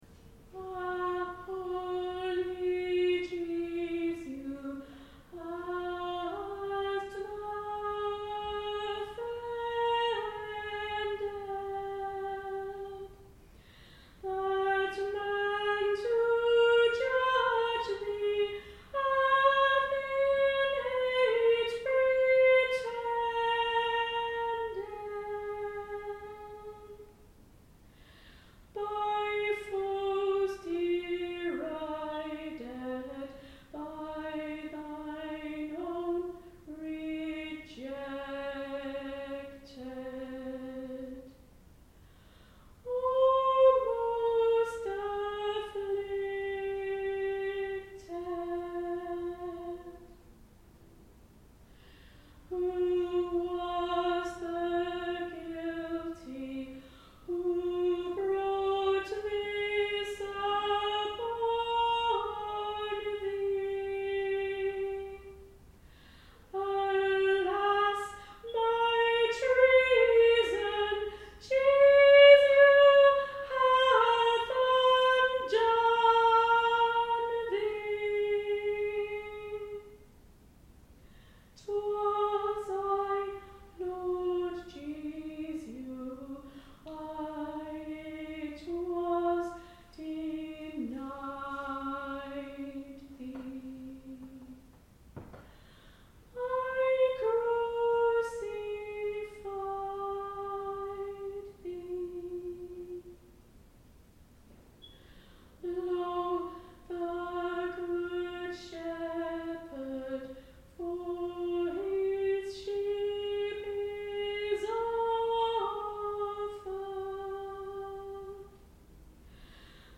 Ah! Holy Jesu, how hast thou offended? One of my solos from a Good Friday service on 18 April 2025